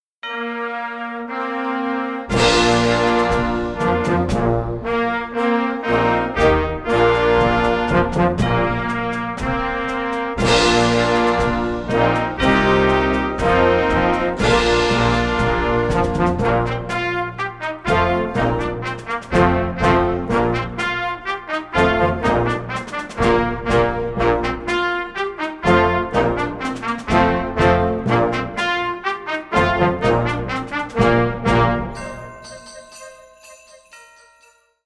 Kategorie Blasorchester/HaFaBra
Unterkategorie Konzertmusik
Besetzung Ha (Blasorchester)